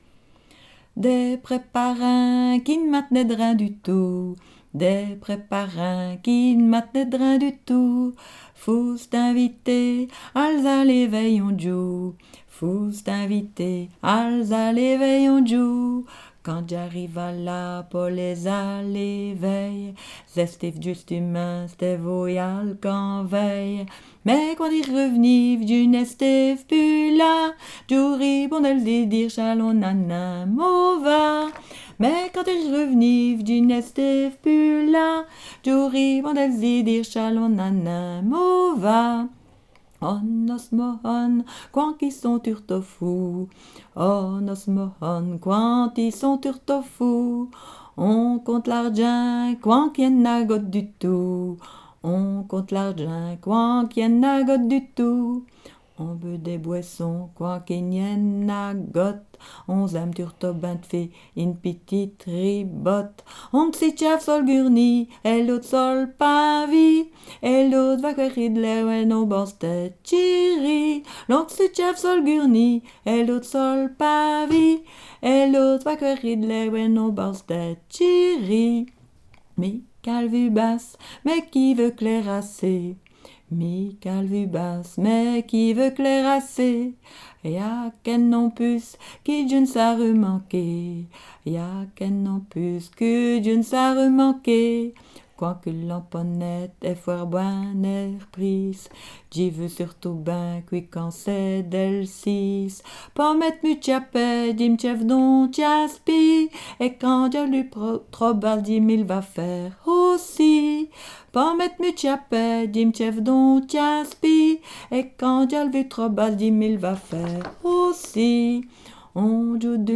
Genre : chant
Type : chanson narrative ou de divertissement
Lieu d'enregistrement : Malmedy
Chanson énumérative.